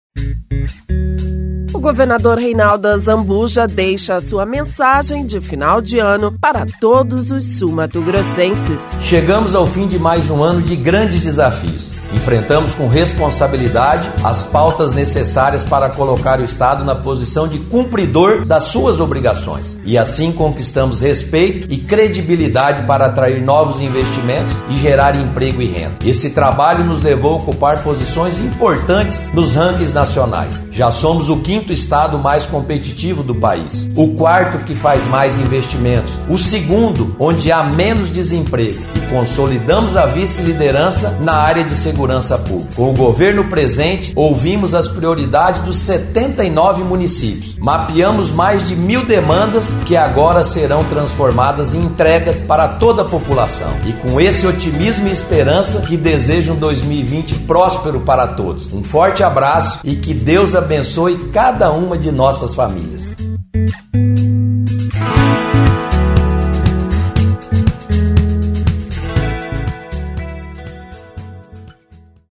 Mensagem de fim de ano do governador Reinaldo Azambuja